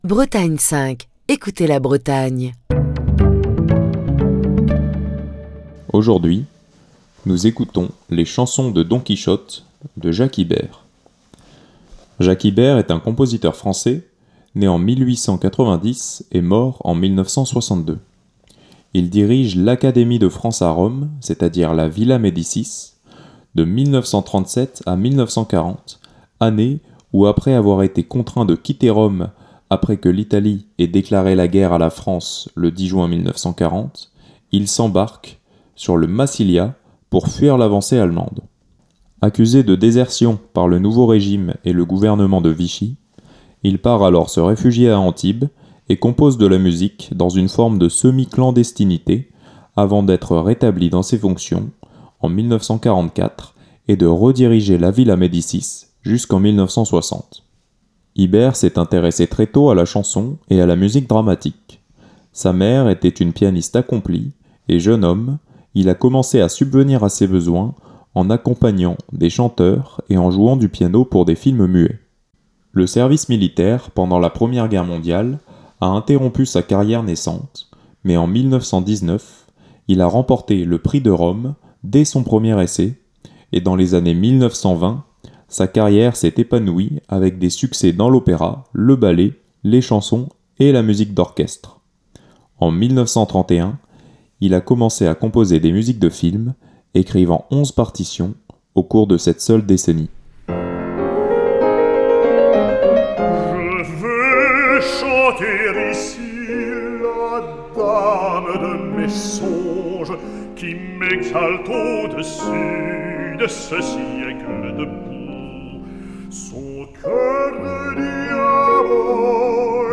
baryton-basse